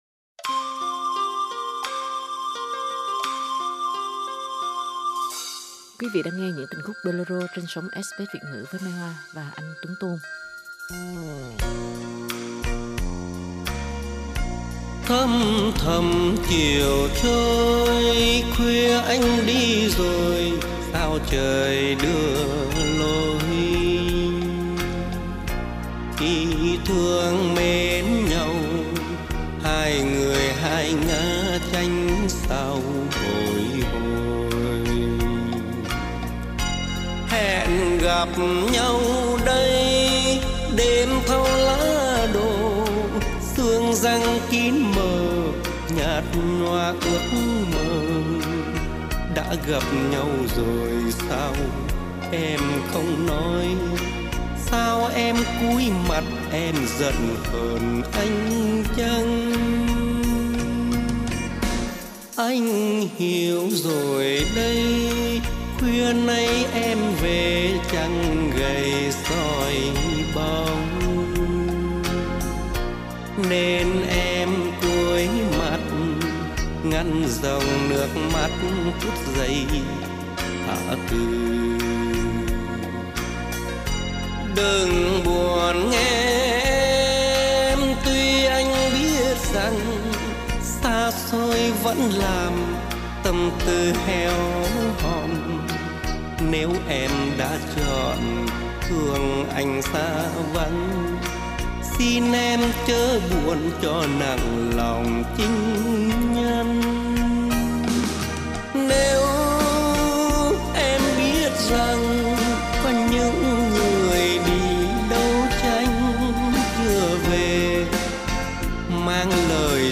ba nam đại danh ca
qua các giọng ca tài danh